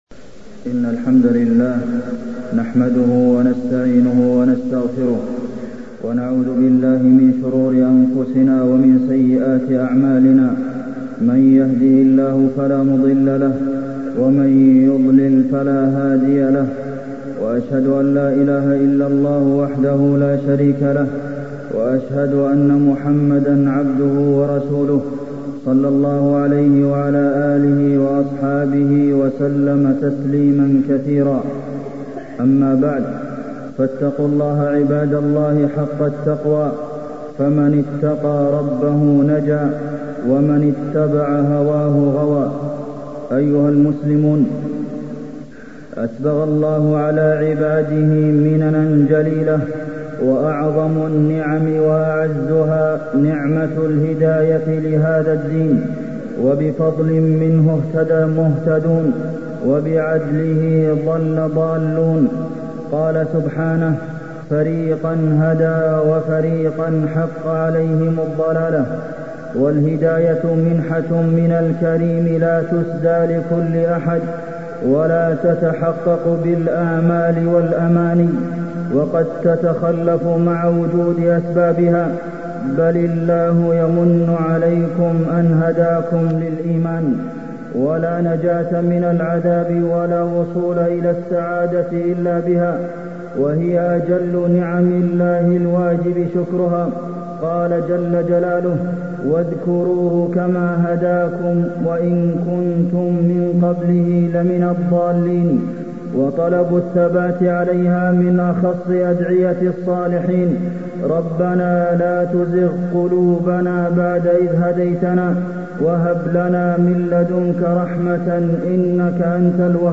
تاريخ النشر ١١ رجب ١٤٢٥ هـ المكان: المسجد النبوي الشيخ: فضيلة الشيخ د. عبدالمحسن بن محمد القاسم فضيلة الشيخ د. عبدالمحسن بن محمد القاسم مجالسة العلماء